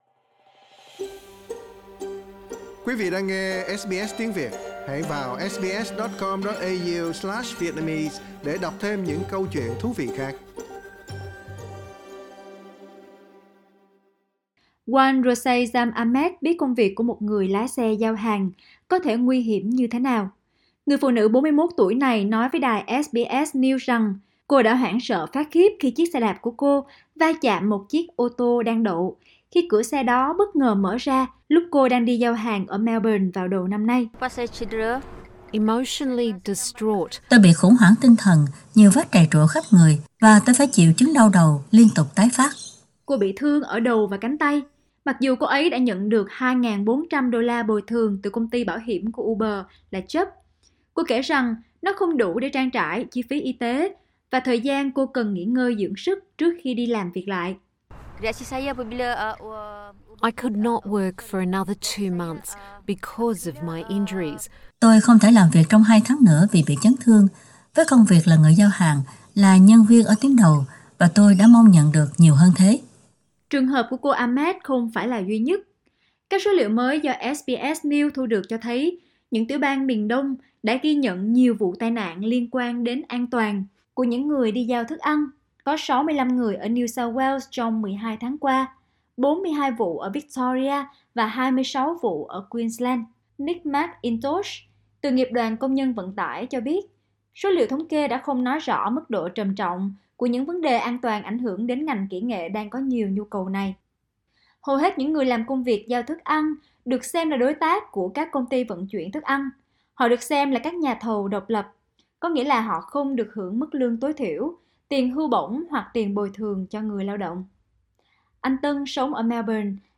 Tình trạng thiếu các biện pháp bảo hộ an toàn trong lĩnh vực giao thức ăn trực tuyến đang được giám sát chặt chẽ sau một loạt các vụ tử vong gần đầy của các nhân viên giao hàng. SBS Việt Ngữ cũng có cuộc trò chuyện ngắn với người Việt ở Melbourne về công việc này.